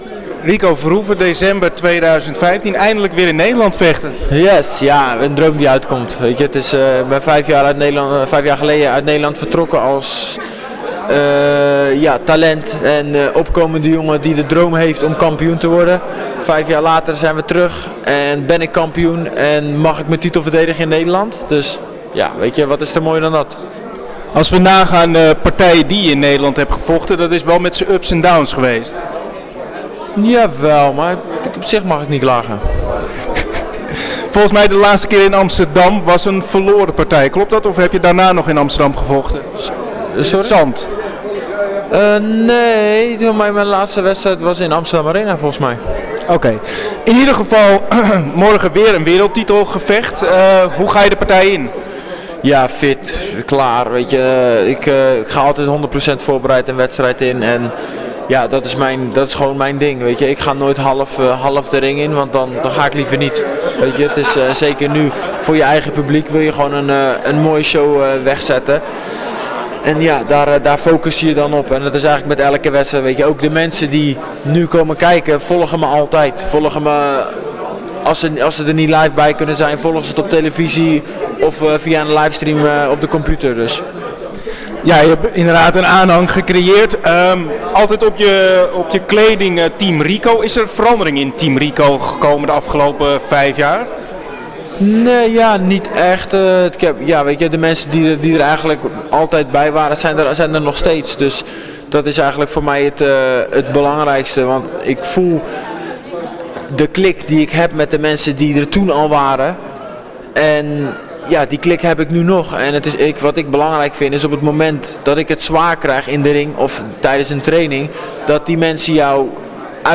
Interview 2016 Verhoeven Glory Collision tegen Badr Hari.